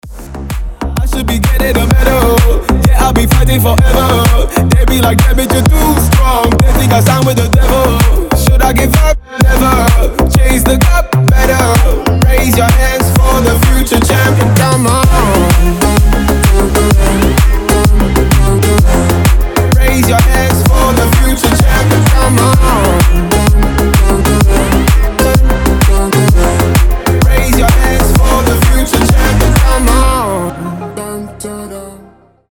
• Качество: 320, Stereo
басы
воодушевляющие
slap house